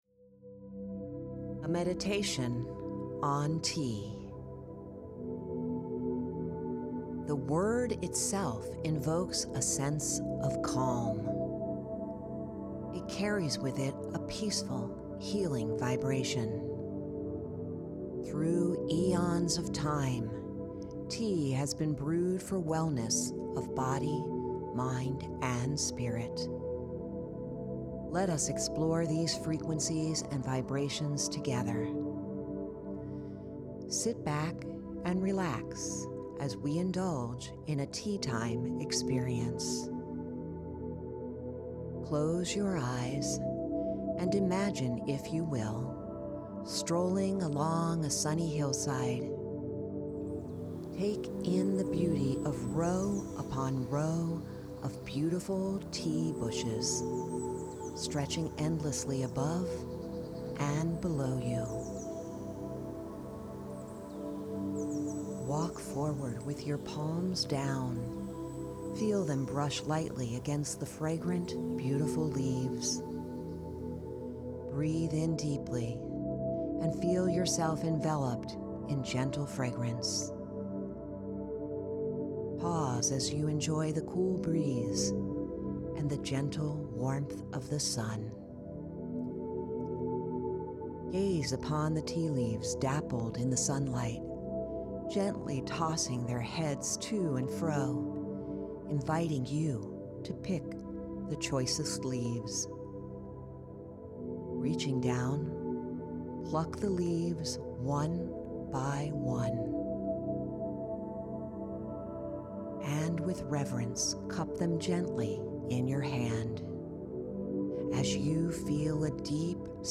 Food Healing Oracle Deck Tea Time Meditation
FHOD-tea-time-meditation.mp3